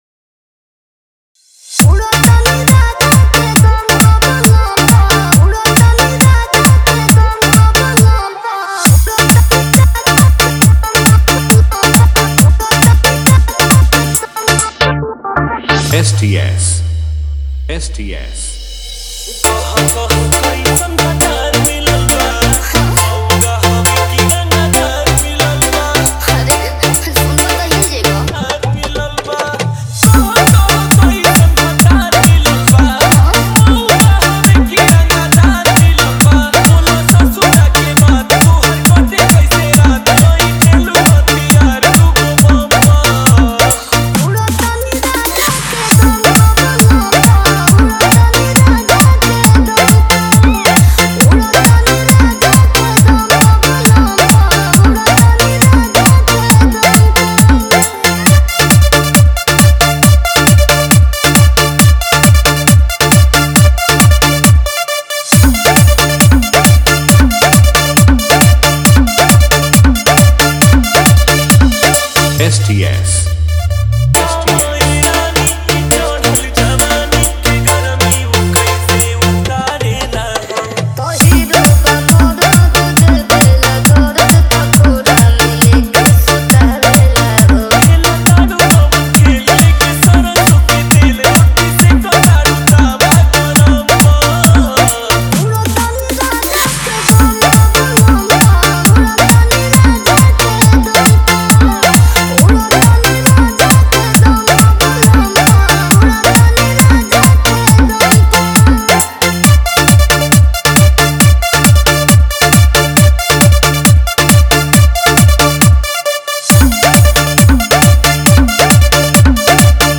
Bhojpuri DJ remix mp3 song